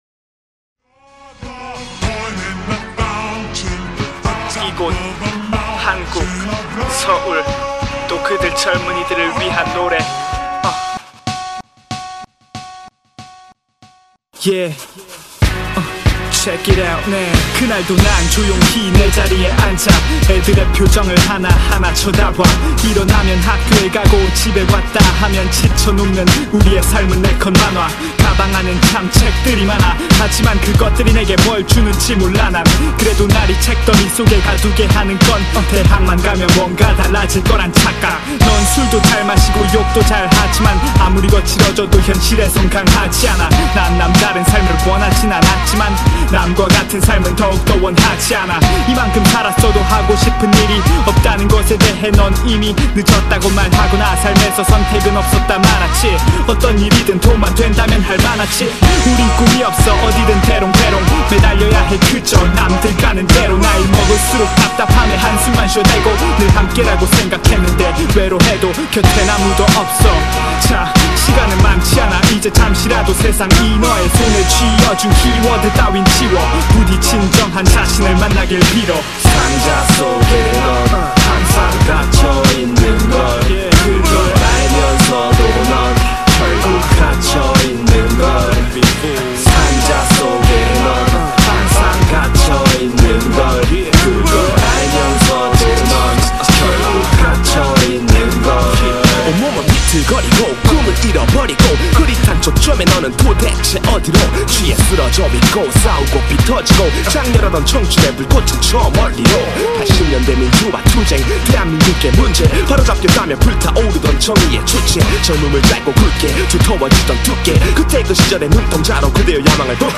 • [국내 / REMIX.]